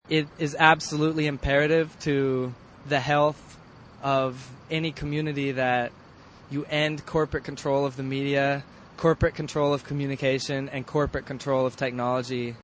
We'll begin with cuts from interviews done with a handful of Mosquito Fleet participants; it was hard to catch people flitting back and forth between transmitters, and some people don't want any publicity.